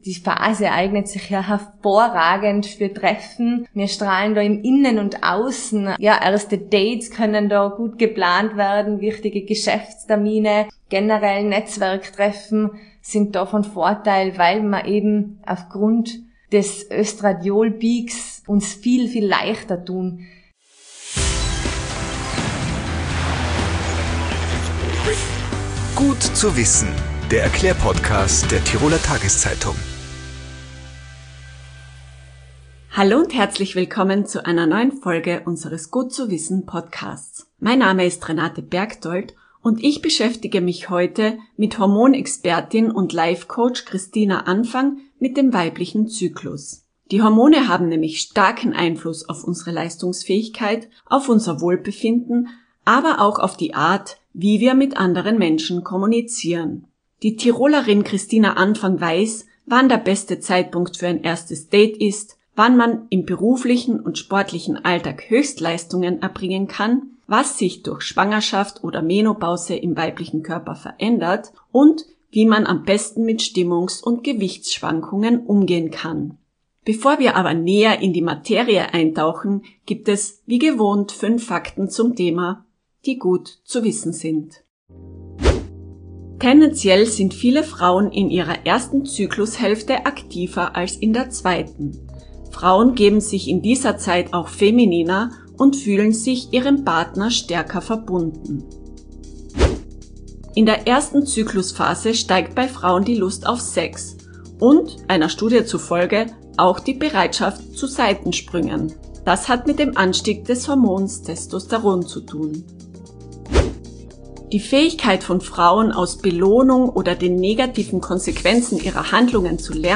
Der "Gut zu wissen"-Podcast Tipps, Erklärungen und Erfahrungen: Wöchentlich gibt es im "Gut zu wissen"-Podcast der Tiroler Tageszeitung interessante und unterhaltsame Gespräche mit Experten oder Betroffenen rund um die Themen Gesundheit, Lifestyle, Ernährung, Gesellschaft, Freizeit & Beruf, Familie & Beziehungen und sonstige wichtige Alltagsthemen.